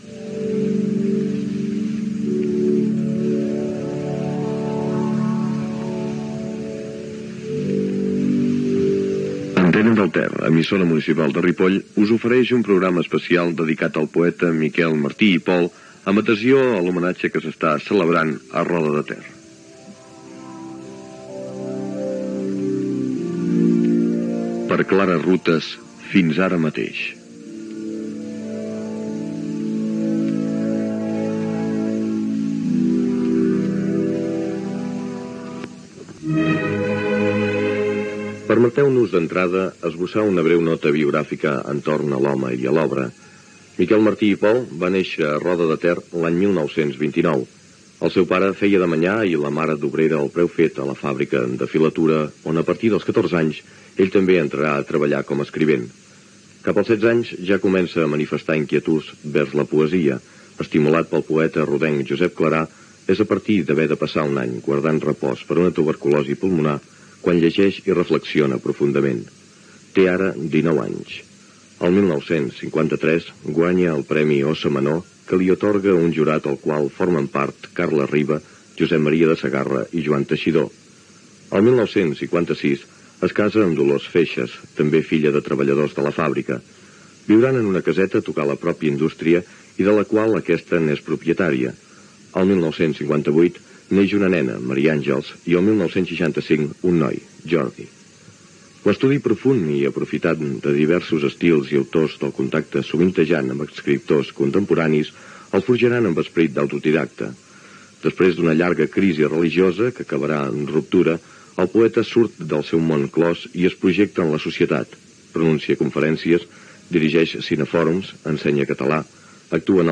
d5b855e47e1e608ecab304f57e40d138bdde1cb5.mp3 Títol Antena del Ter Emissora Antena del Ter Titularitat Pública municipal Descripció Programa especial dedicat al poeta Miquel Martí i Pol. Dades biogràfiques i lectura d'alguns dels seus poemes i escrits